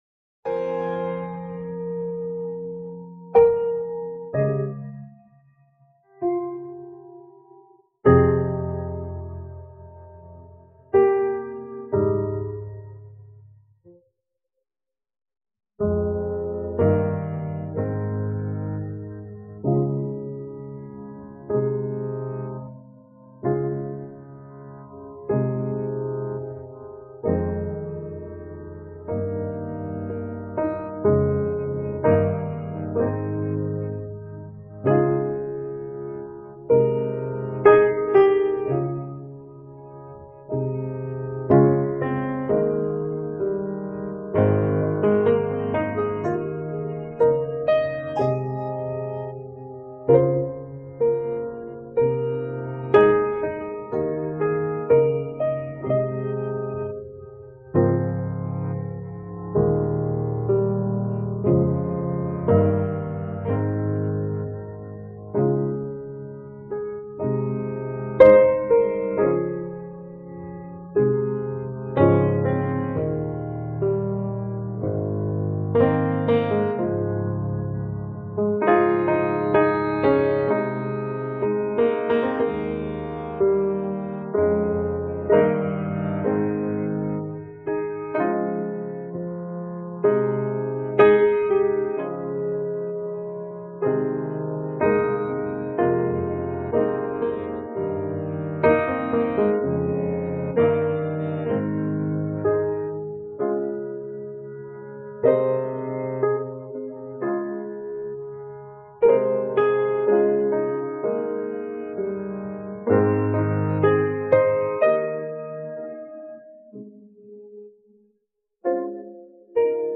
emotive piano track